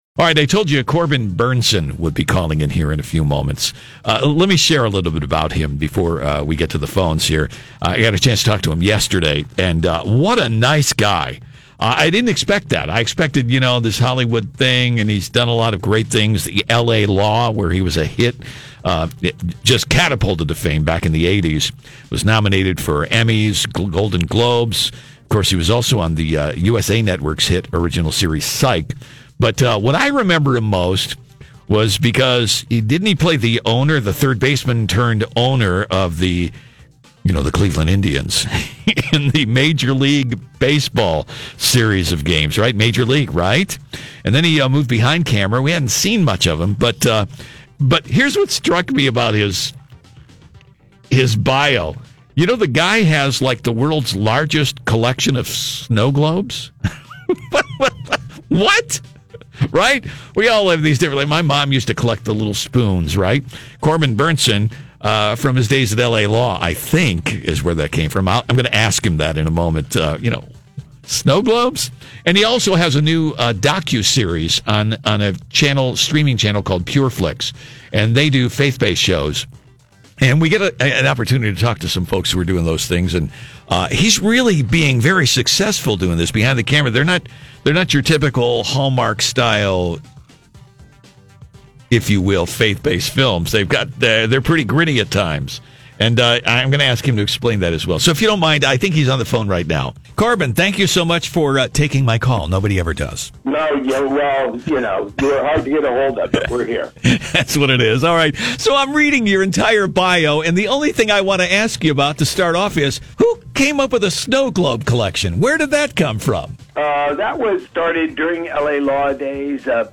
INTERVIEW https